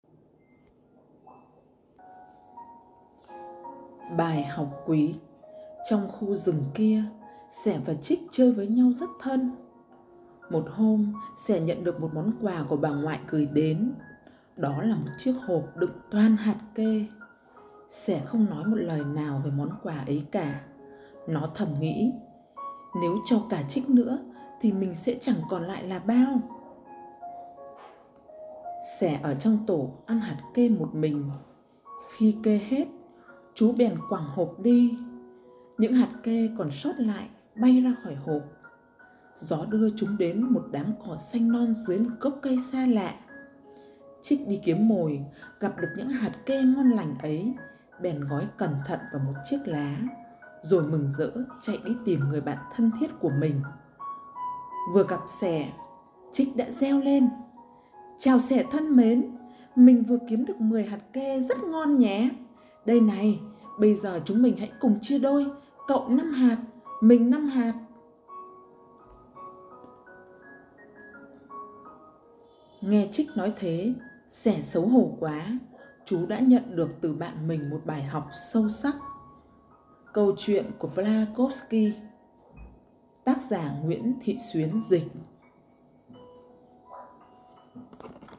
Sách nói | BÀI HỌC QUÝ - ĐẠO ĐỨC 2